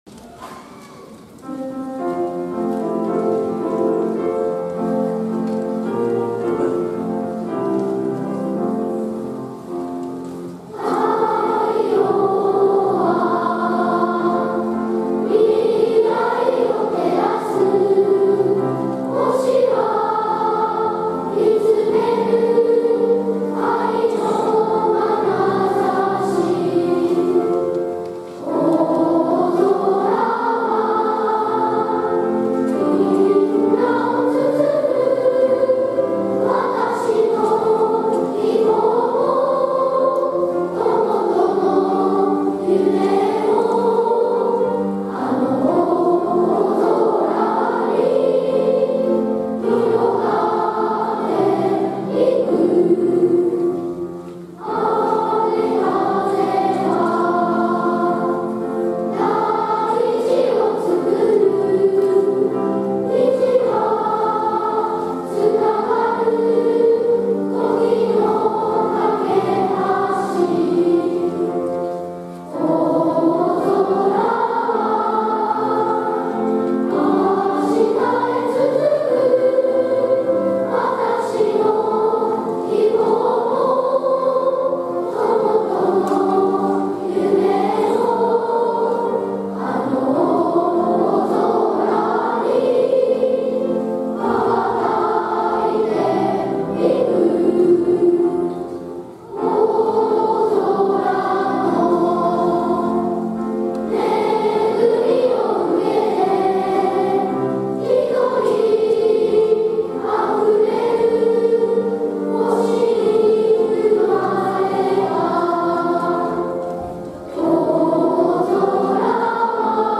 校歌」の全校2部合唱です。
大空のみんなが大切にしている校歌をみなさんと一緒に歌い、会場がひとつになります♪